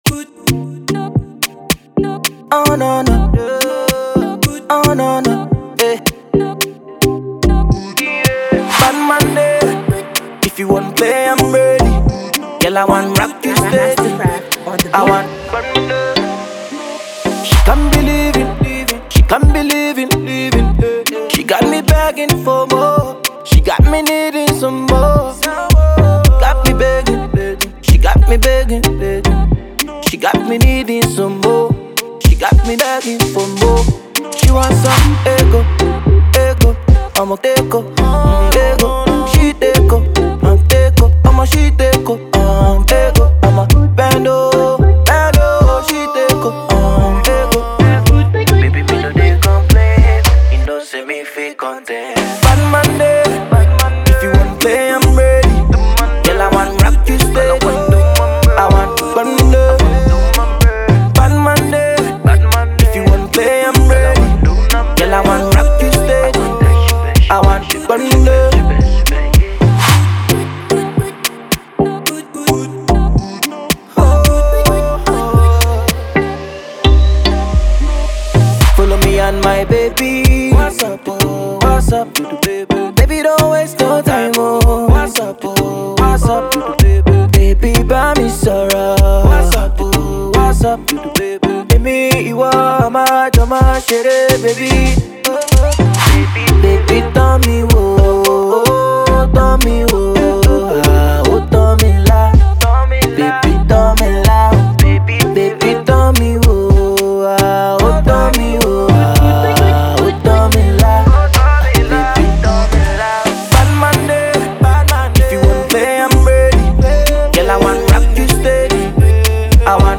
Afrobeats vocal powerhouse
infectious percussion and hard hitting synths